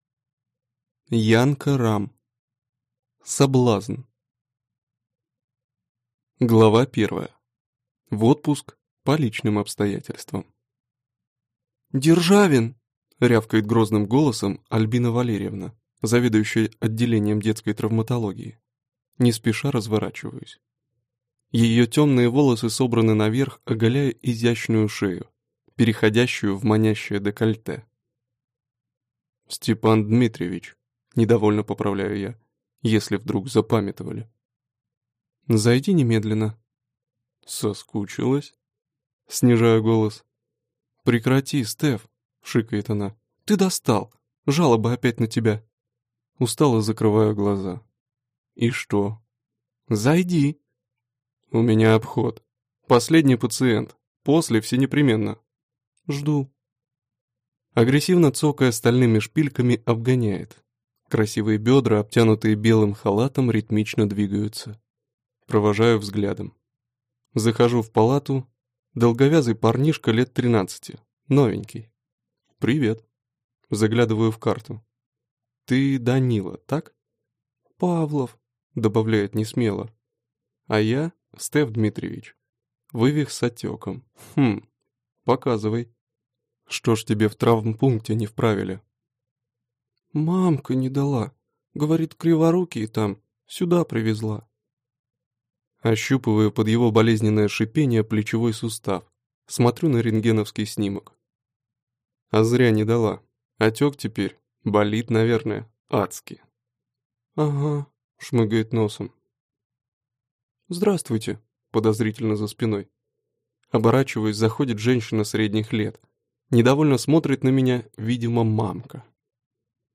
Aудиокнига Соблазн